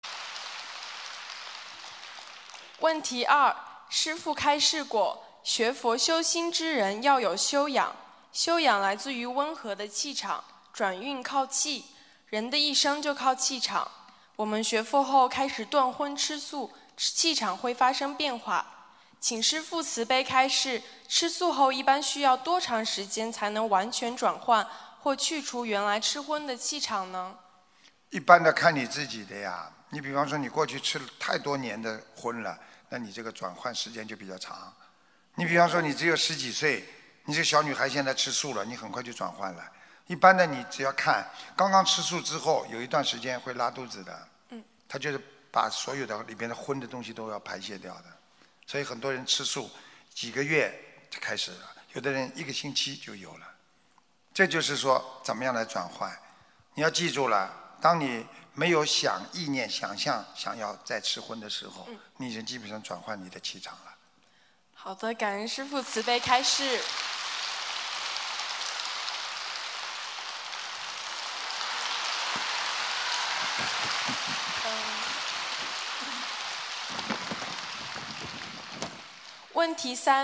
Pertanyaan di Seminar Dharma
Pertanyaan di Acara Pertemuan Umat Buddhis Sedunia di Melbourne – Australia, 6 Desember 2019